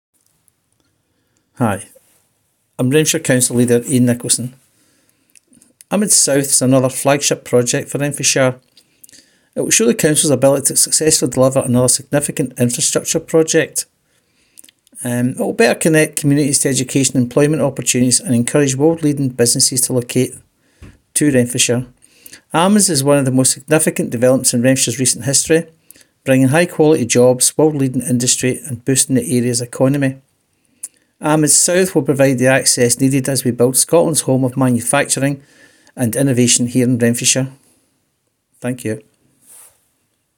Radio clip included - £59million project will create new road bridge and riverside route to connect Paisley with the Advanced Manufacturing Innovation District Scotland (AMIDS)
Renfrewshire Council Leader Iain Nicolson - AMIDS South announcement